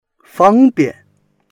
fang1bian4.mp3